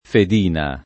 vai all'elenco alfabetico delle voci ingrandisci il carattere 100% rimpicciolisci il carattere stampa invia tramite posta elettronica codividi su Facebook fedina [ fed & na ] s. f. («certificato penale»; «basetta»)